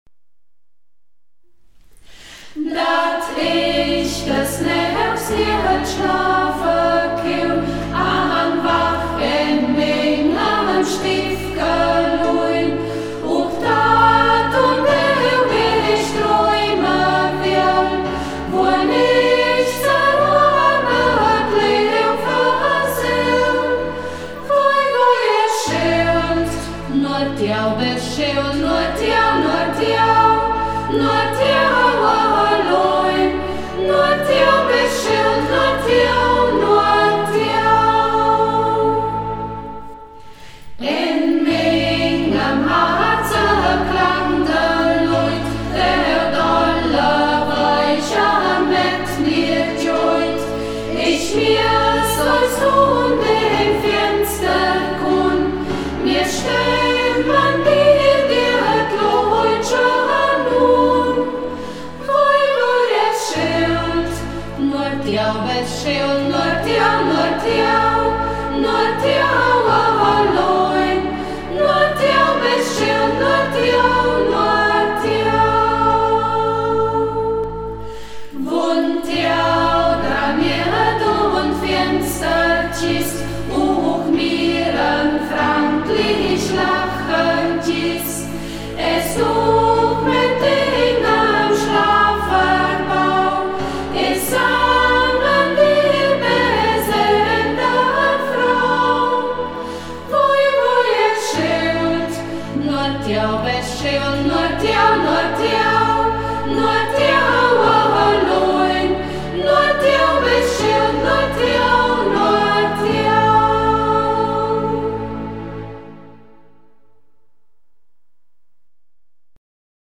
Lieder in Burgberger Mundart, gesungen von der Burgberger Singgruppe